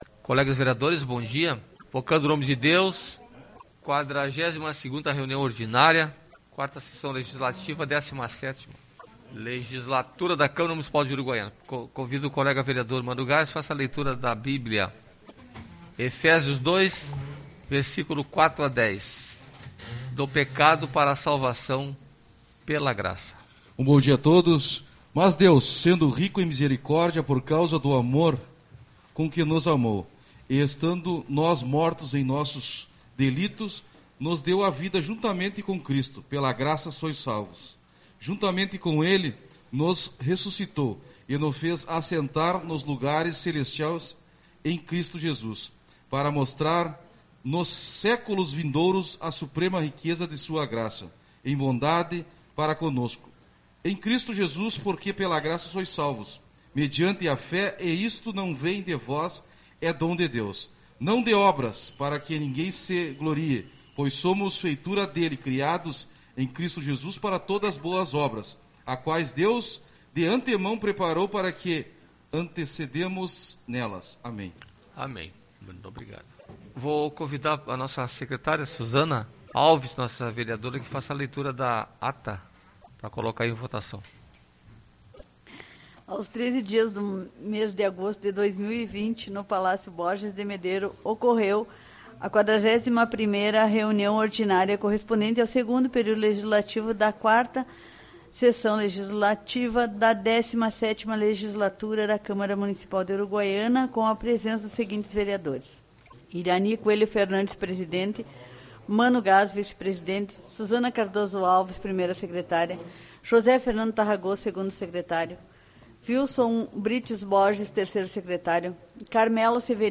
18/08 - Reunião Ordinária